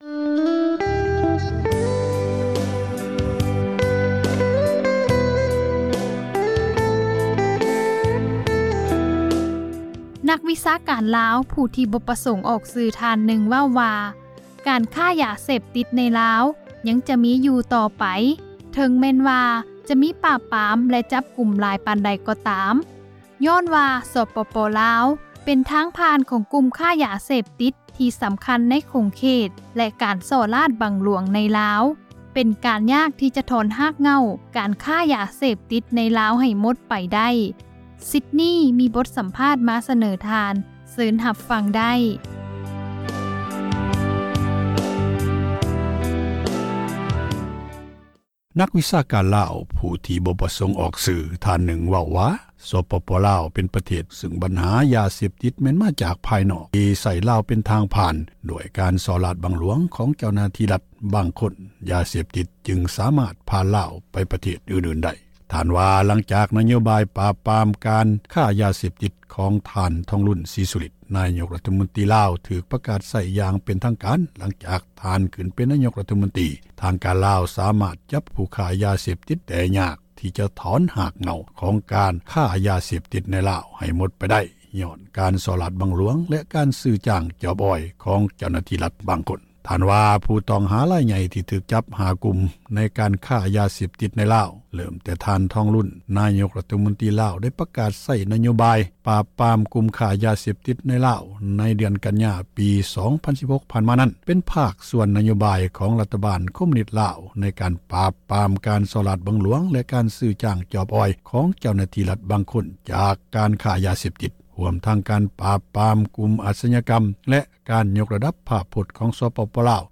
ນັກວິຊາການລາວ ຜູ້ທີ່ບໍ່ປະສົງອອກຊື່ ທ່ານນຶ່ງເວົ້າວ່າ ສປປລາວ ເປັນປະເທດທີ່ສງົບ ຊຶ່ງບັນຫາ ຢາເສບຕິດ ແມ່ນມາຈາກພາຍນອກ ທີ່ໃຊ້ລາວເປັນທາງຜ່ານ ດ້ວຍການ ສໍ້ຣາສບັງຫລວງ ຂອງເຈົ້າຫນ້າທີ່ຣັດ ບາງກຸ່ມ ຢາເສບຕິດ ຈຶ່ງສາມາດຜ່ານລາວ ໄປປະເທດອື່ນໆໄດ້.